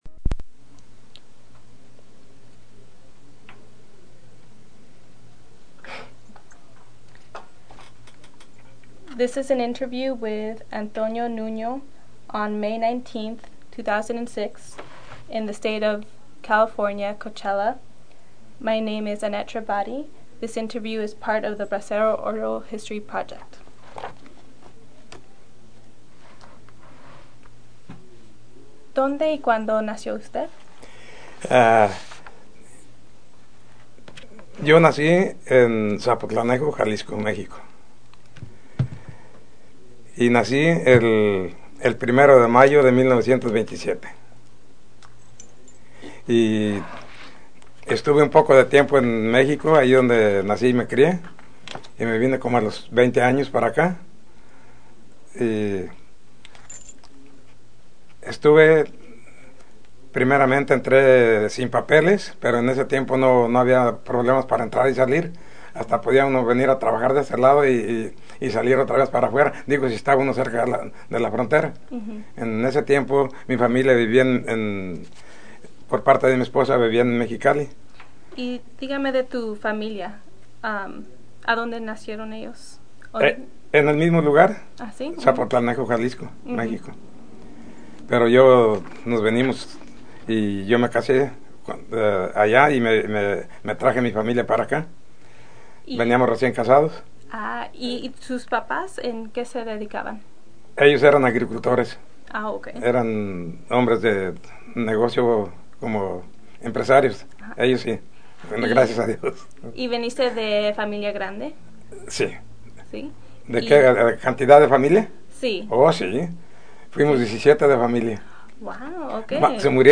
Location Coachella, CA Original Format Mini disc